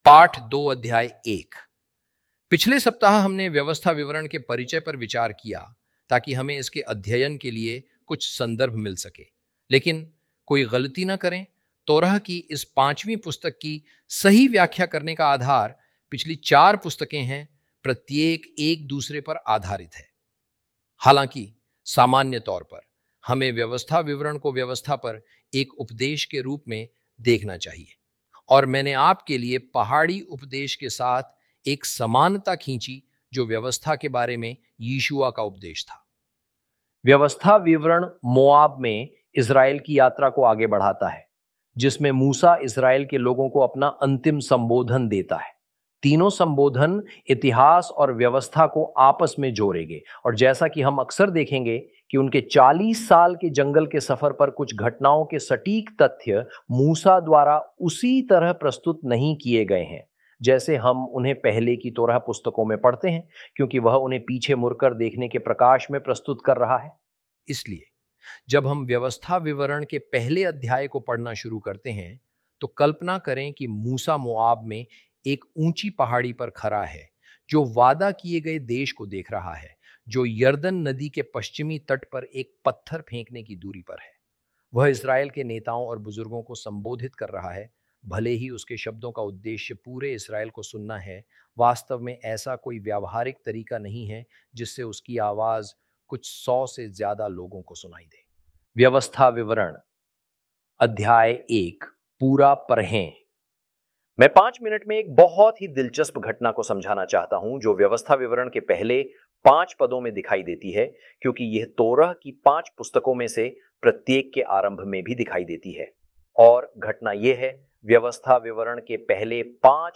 hi-audio-deuteronomy-lesson-2-ch1.mp3